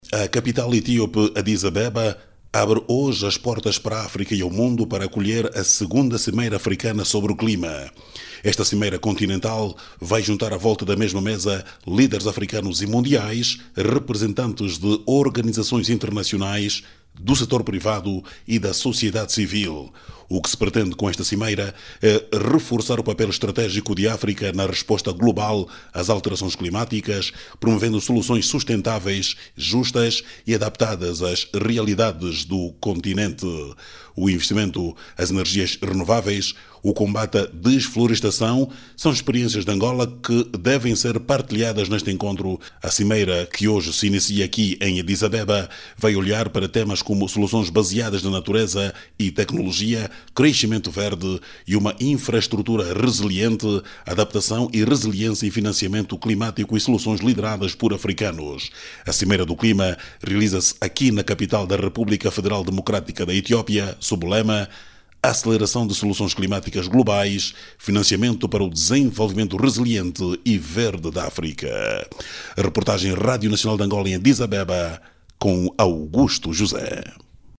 Segundo João Lourenço, é necessário redefinir os modelos de cooperação, tendo em vista o desenvolvimento conjunto e sustentável das duas regiões. Clique no áudio abaixo e ouça a reportagem